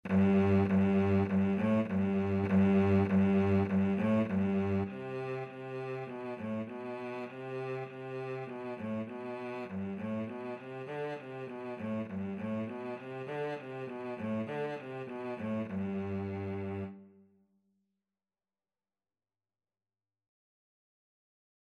2/4 (View more 2/4 Music)
G3-D4
Cello  (View more Beginners Cello Music)
Classical (View more Classical Cello Music)